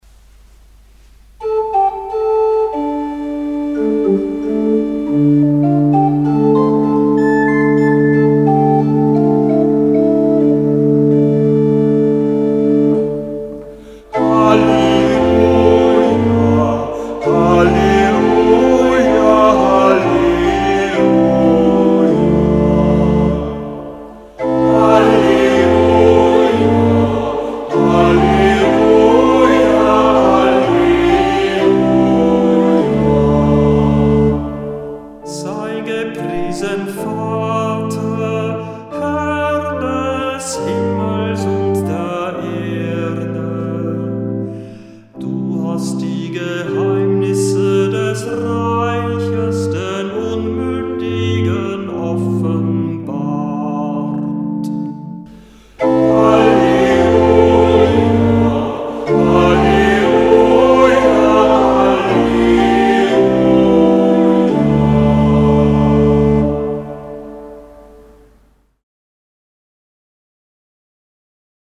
Halleluja aus dem Gotteslob